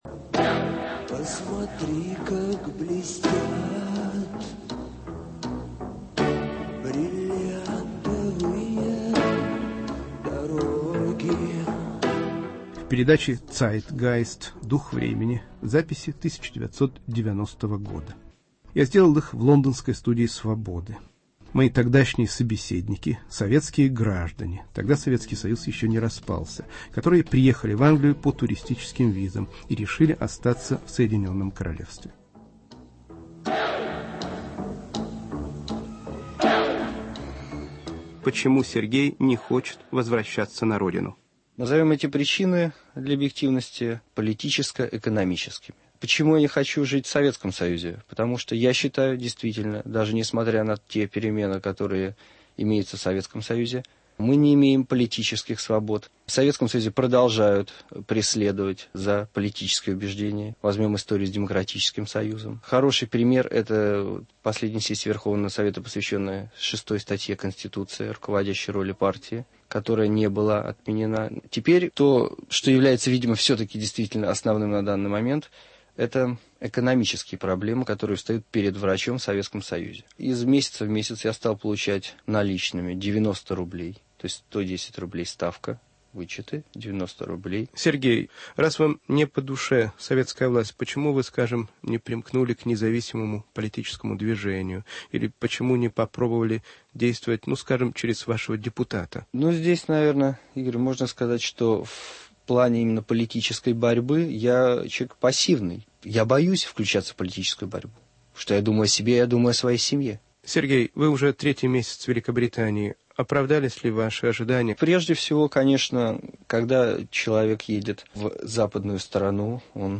Русские в Лодоне: интервью начала 90-х и новые инетрвью с выходцами из России, живущими в Лондоне.